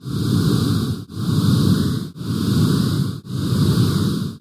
Alien creature breathes in long heavy breaths. Loop Monster, Creatures Breathe, Alien Eerie, Breathe